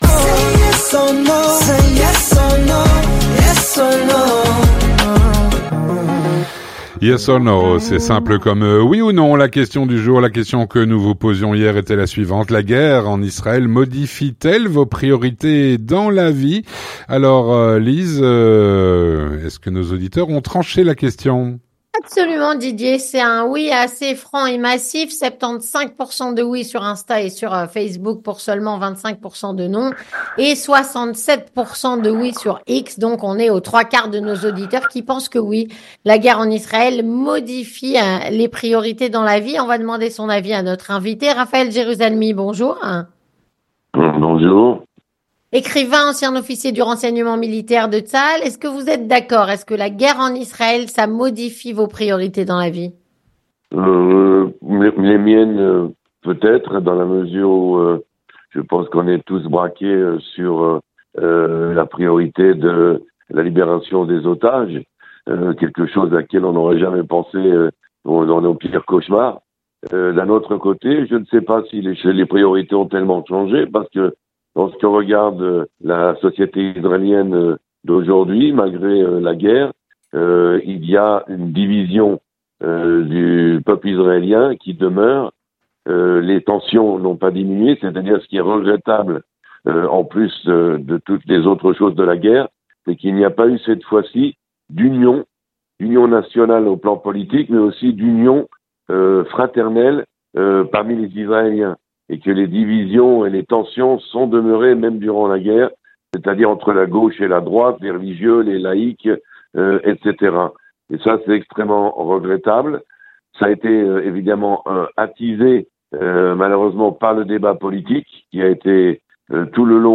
Raphaël Jérusalmy, ancien officier du renseignement militaire de Tsahal, répond à “La Question Du Jour”.